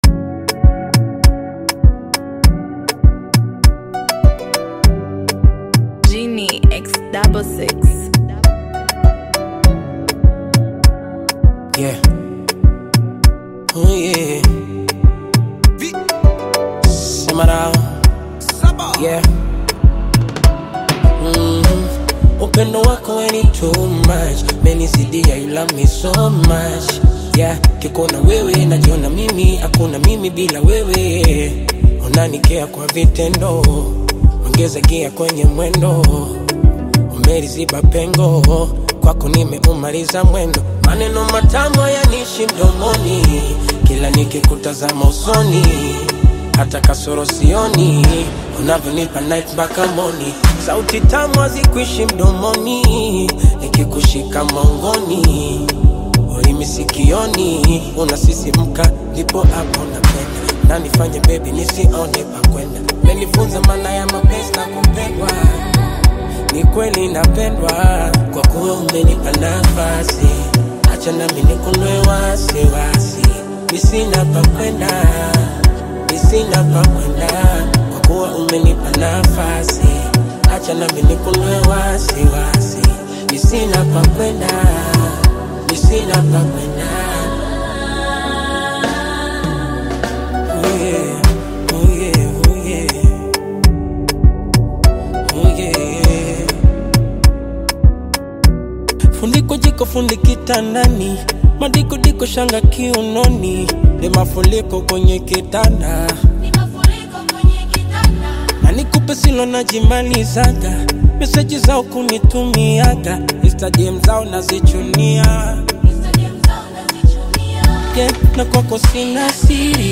uplifting Afro-Pop/Bongo Flava single